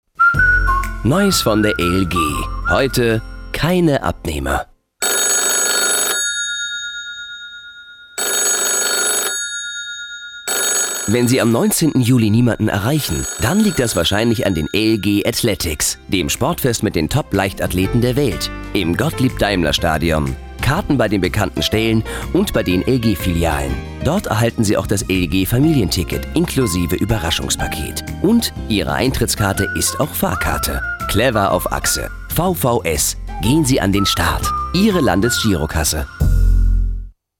Genre: Sprachprobe.